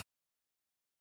HippoSnores-003.wav